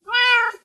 meow2.ogg